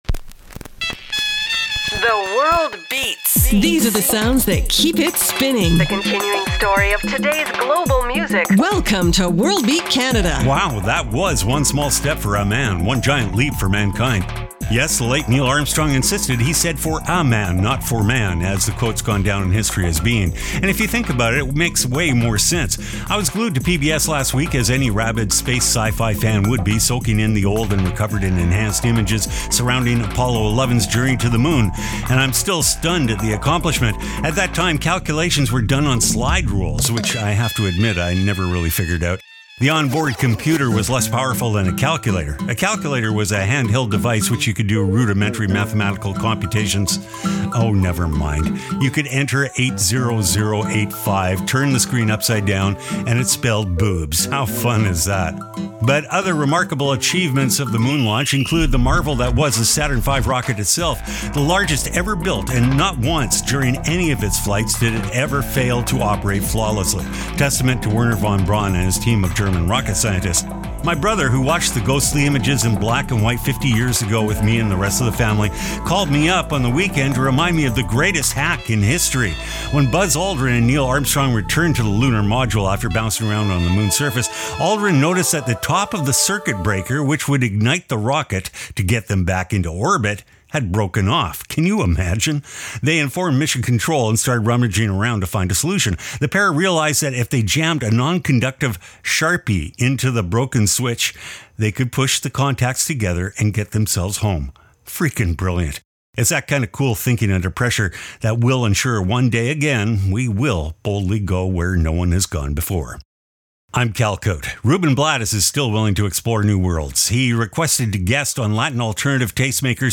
exciting global music alternative to jukebox radio